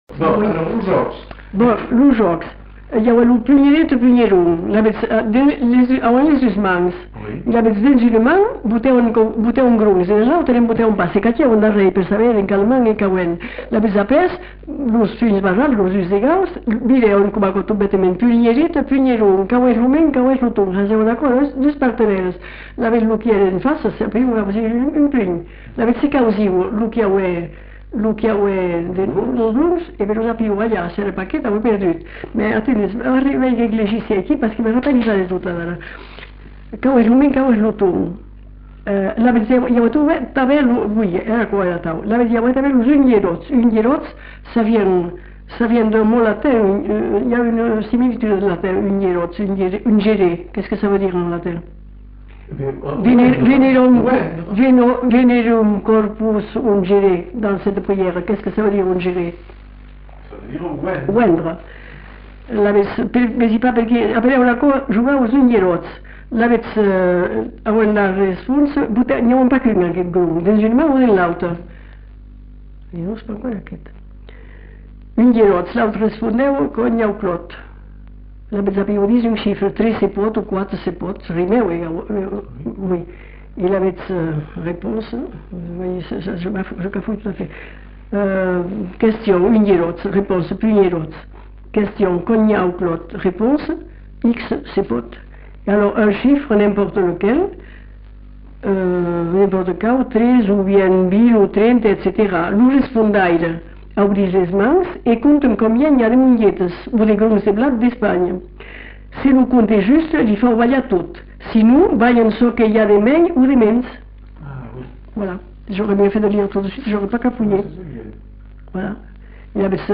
Lieu : Lerm-et-Musset
Genre : témoignage thématique